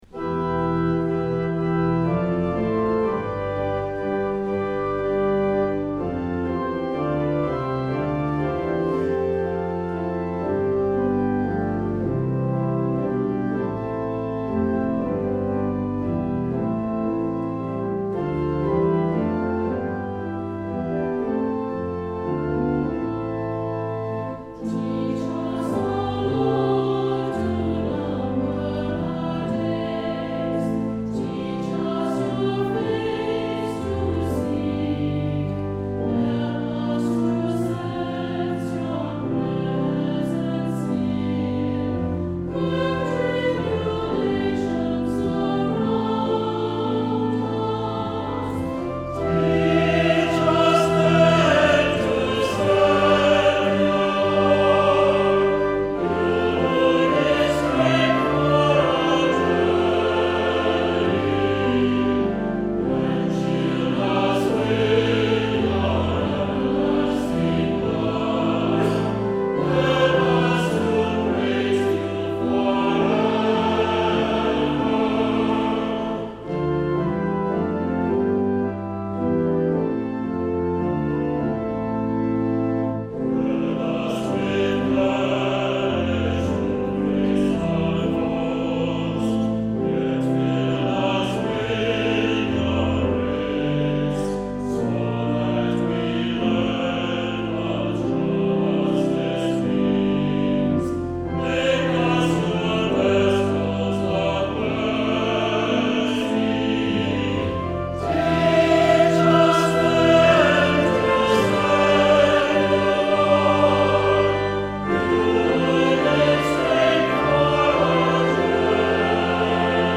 Voicing: 2 PartSATB/p